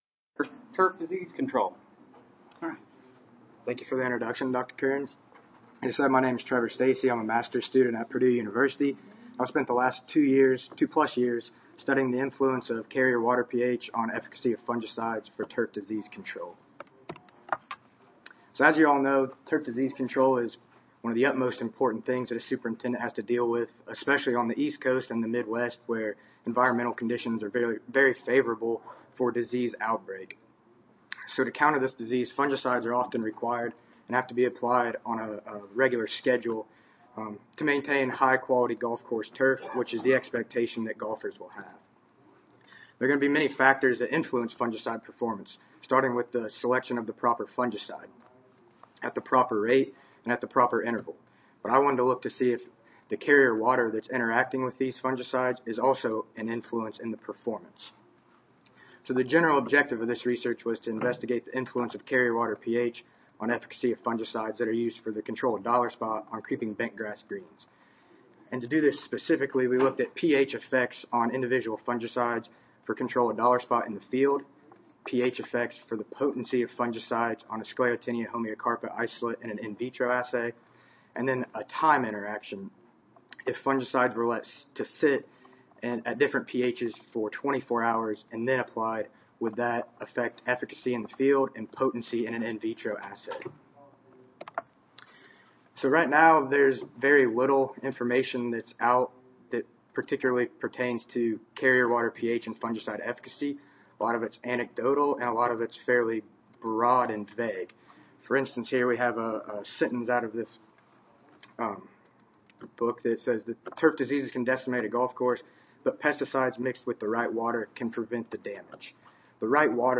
Purdue University Audio File Recorded Presentation